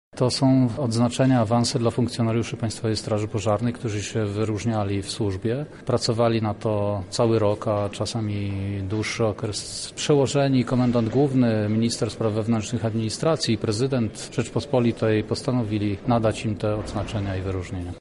– mówi starszy brygadier Grzegorz Alinowski, Lubelski Komendant Wojewódzki Państwowej Straży Pożarnej.